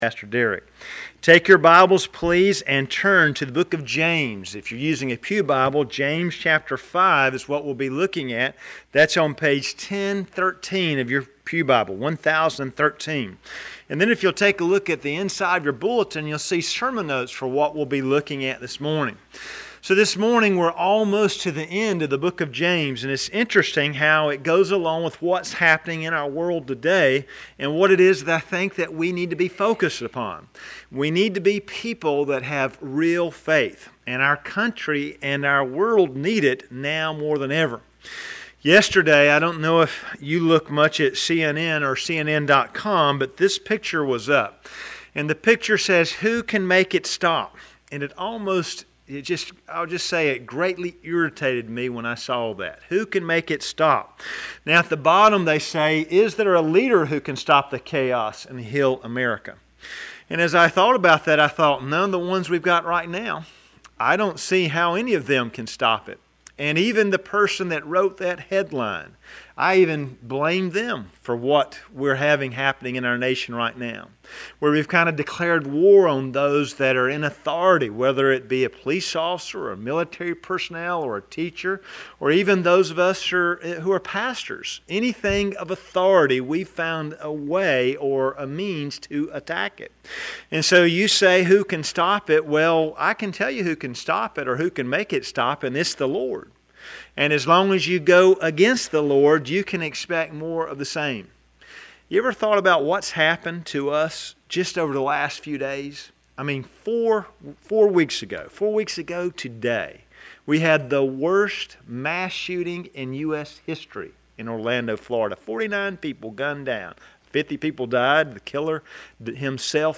Sermon library of Tippett’s Chapel Free Will Baptist Church in Clayton, NC enables listeners to easily browse our Sunday morning sermons and worship services.